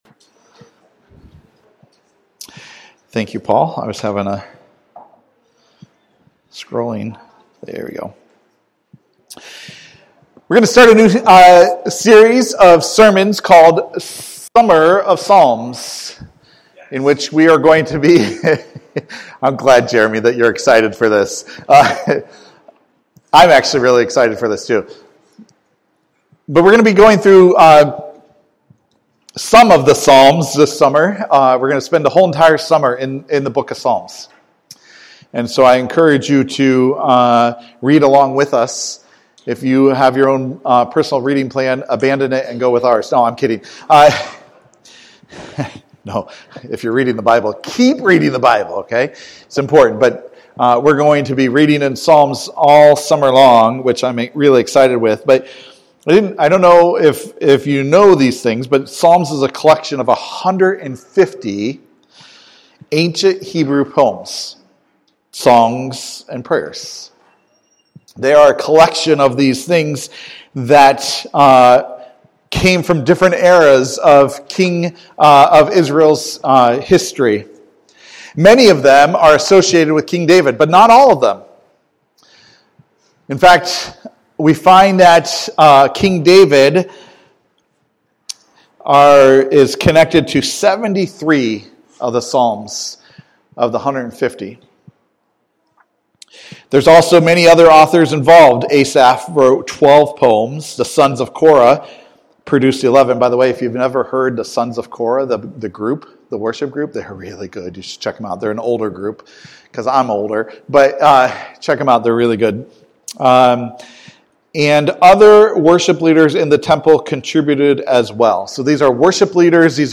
Sermons by Passion Community Church